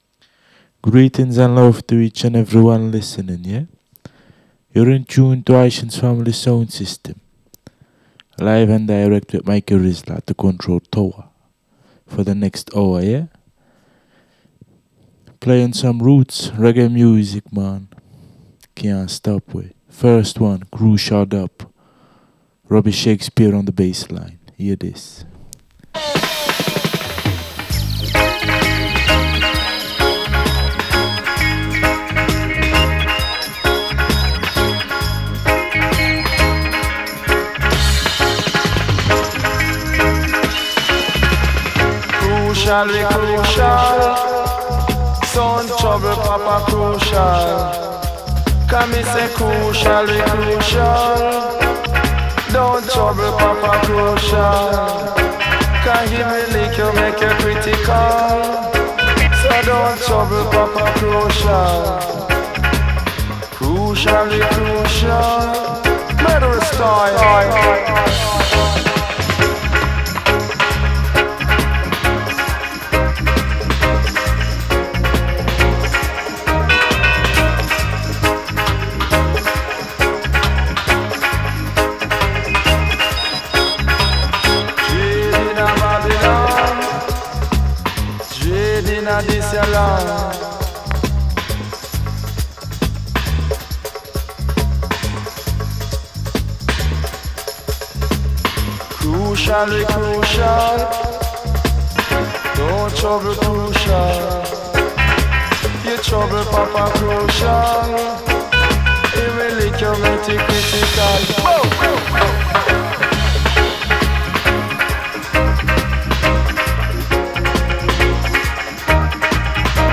Strictly Vinyl Records. From early Roots to deep Dub !
Recorded straight from a 4-way Jored Preamp.